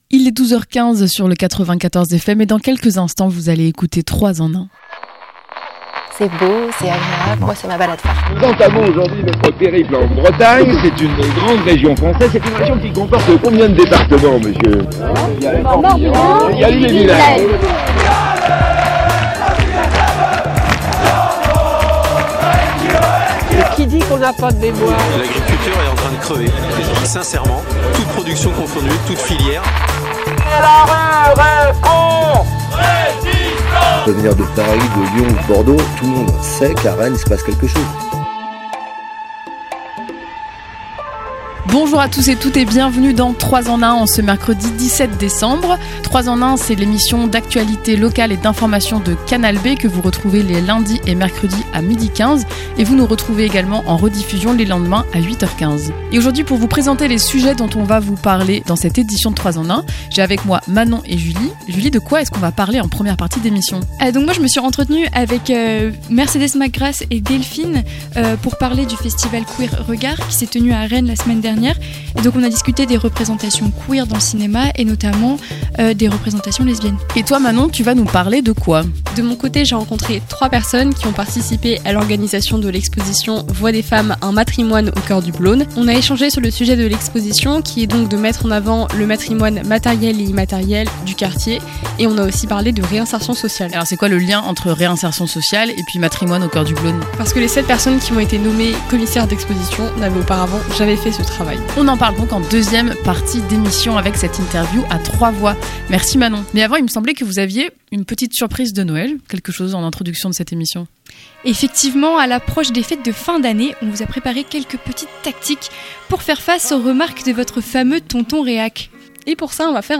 L'interview
Le festival Regard(s) met en avant des œuvres cinématographiques aussi bien contemporaines que plus anciennes, sur les thématiques des sexualités et des identités de genre. Le reportage